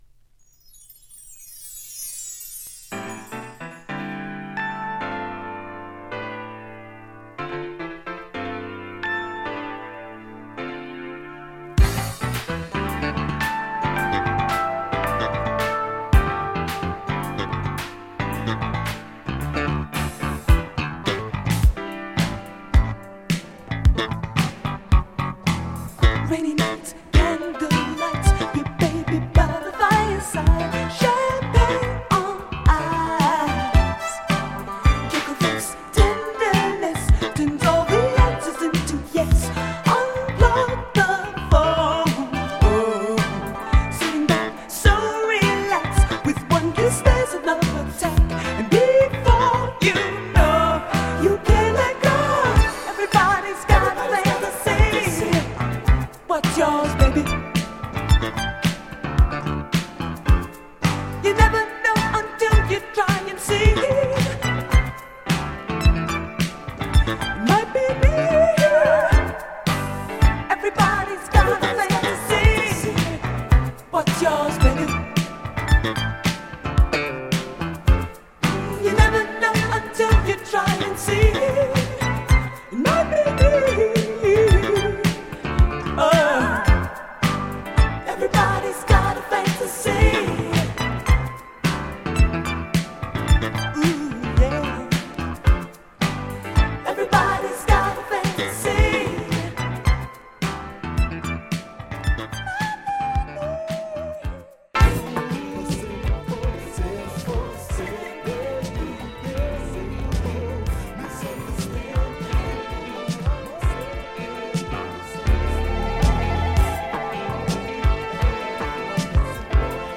SOUL
MODERN FUNK〜URBAN SOUL !!